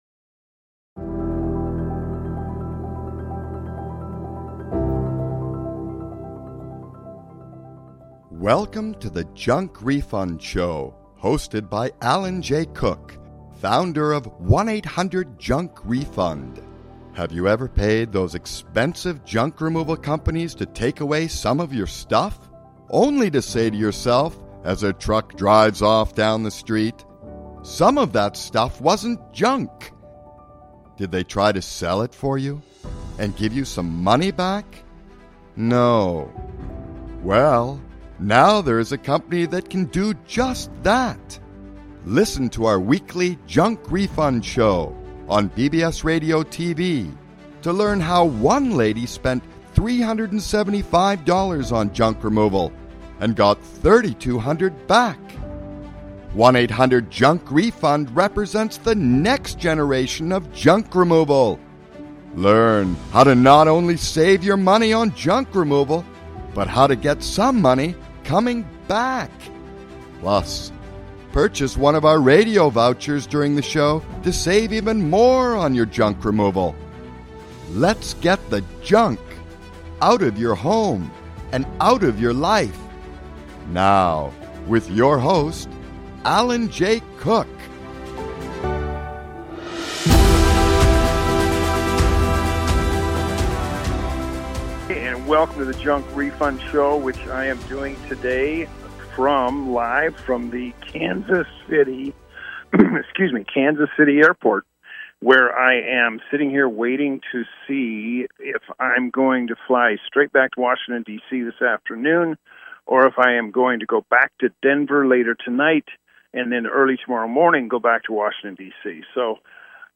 Interview Guest